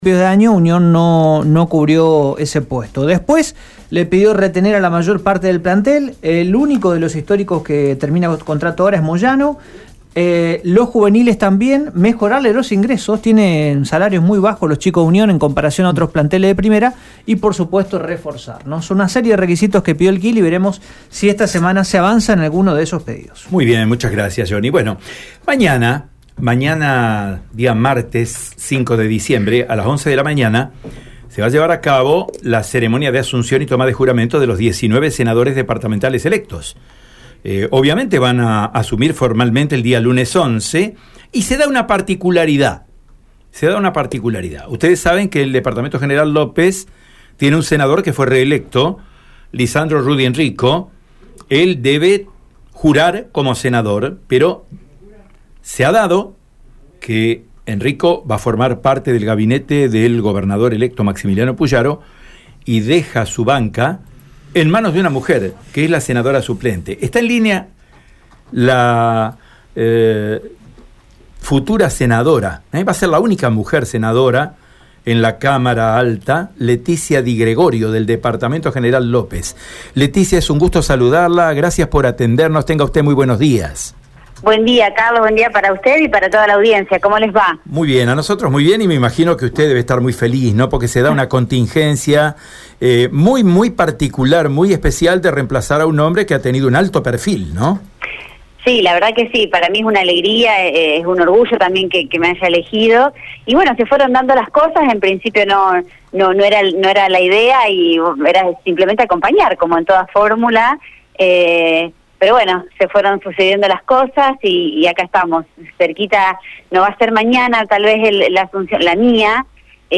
En diálogo con Radio EME, la futura senadora destacó que conoce el cargo tras haber sido la candidata suplente para el Senado, dado que lleva mucho tiempo a la par del senador Lisandro Enrico.
Escuchá la palabra de Leticia Di Gregorio: